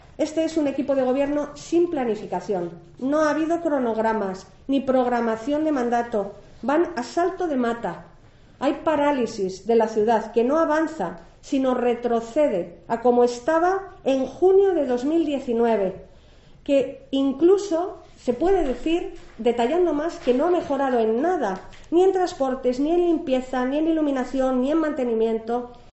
Sonsoles Sánchez-Reyes, portavoz PP. Ciudad paralizada